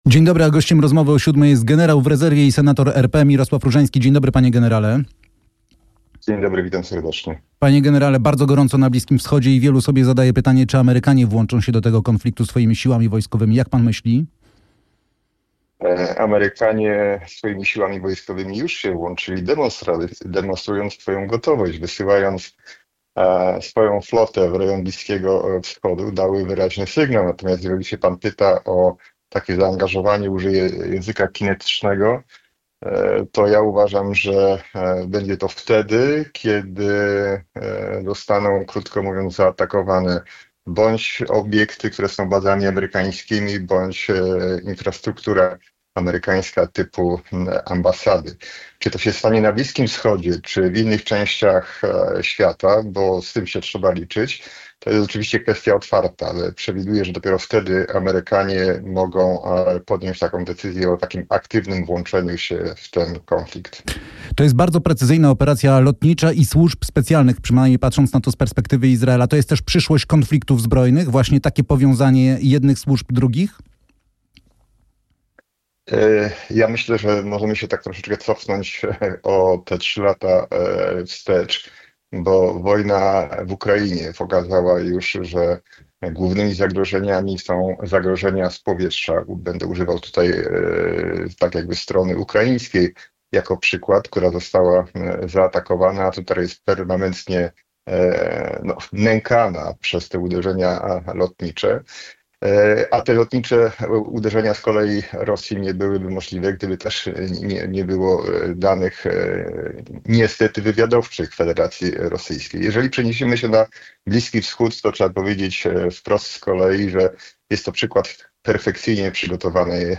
Rozmowa o 7:00 to pierwszy publicystyczny punkt dnia w RMF FM i Radiu RMF24. Aktualne tematy i gorące komentarze.